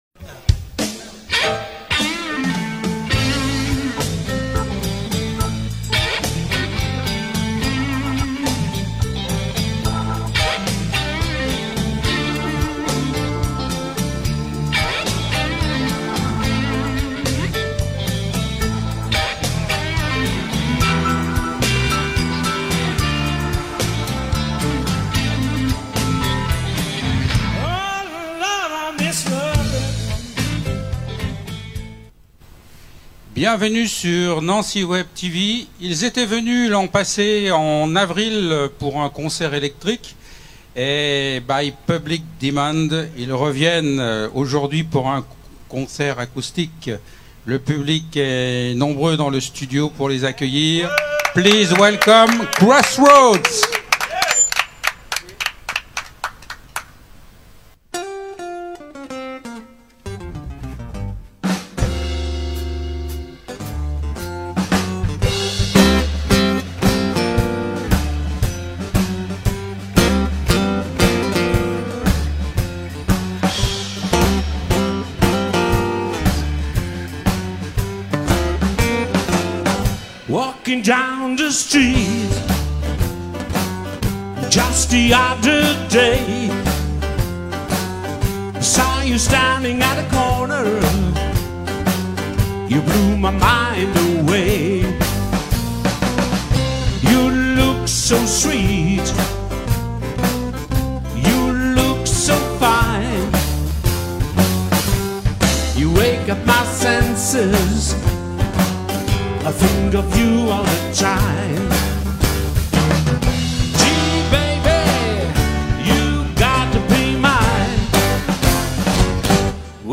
Du traditionnel au blues rock actuel.
L’émission offre un espace aux musiciens Lorrains et à la particularité de présenter de la musique en Live et des interviews.